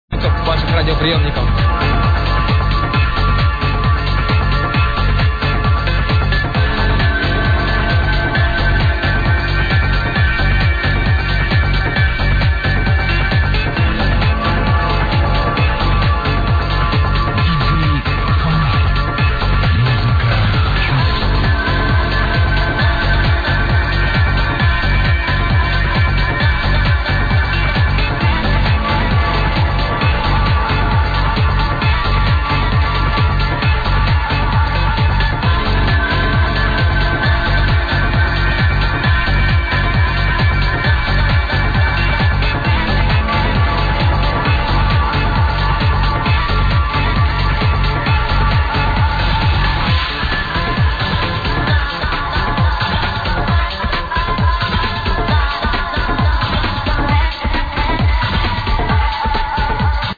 Thumbs up Good progressive track, define it!!!